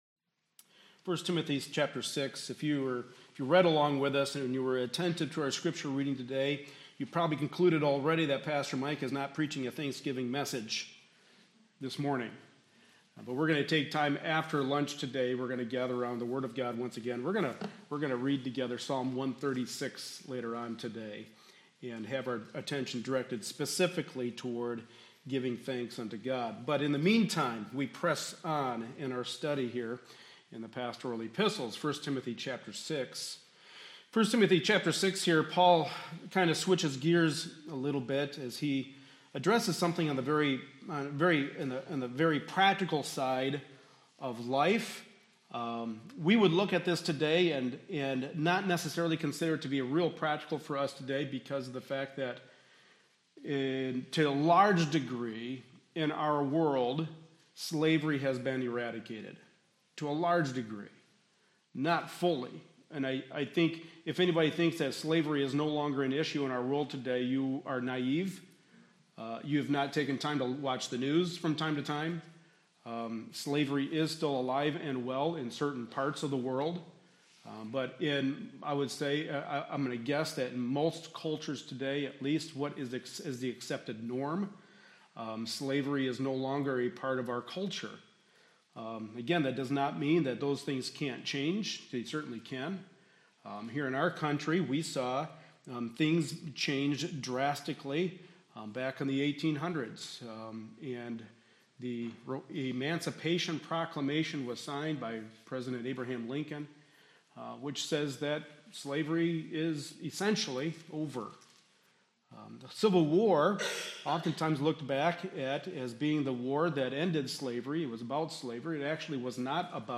Passage: 1 Timothy 6:1-2 Service Type: Sunday Morning Service